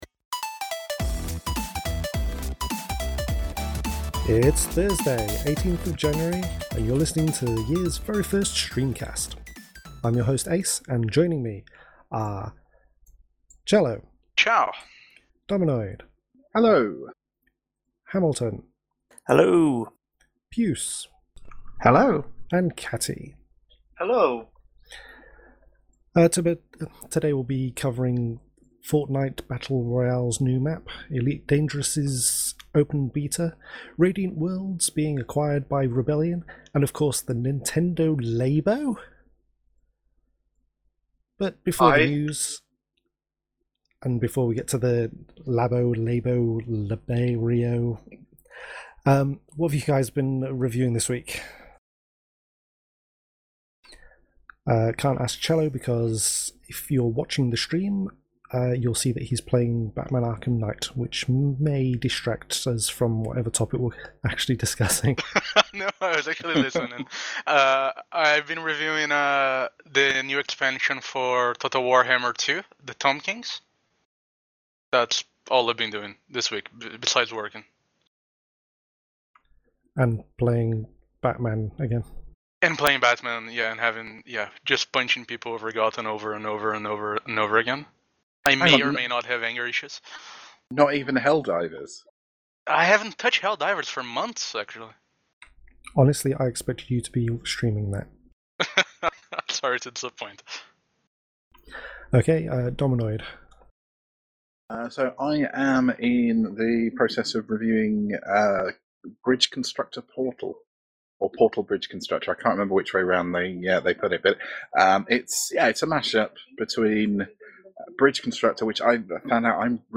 This week on the GrinCast, it was our first livestream of the year!